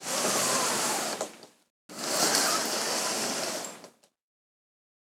Curtains Closing Sound
household